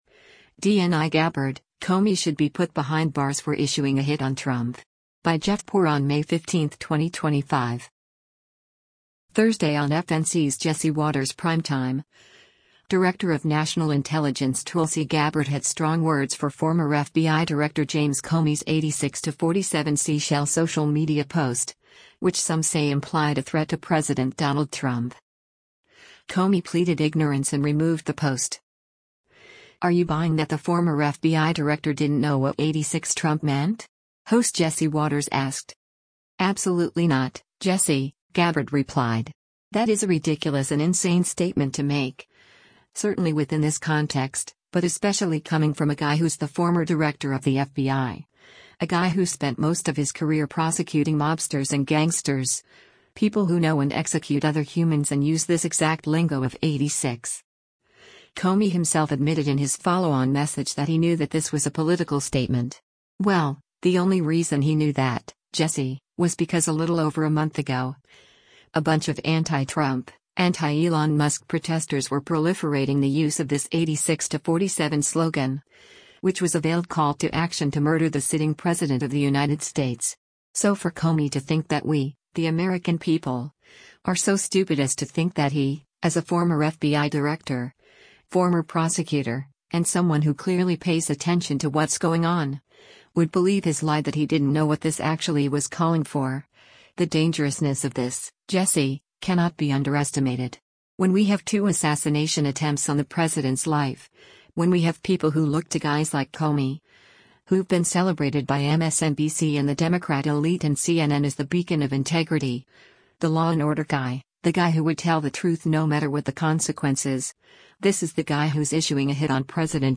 Thursday on FNC’s “Jesse Watters Primetime,” Director of National Intelligence Tulsi Gabbard had strong words for former FBI Director James Comey’s “86-47” seashell social media post, which some say implied a threat to President Donald Trump.